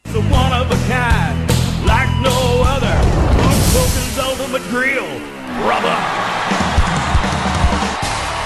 Did I tell you the informercial has a theme song?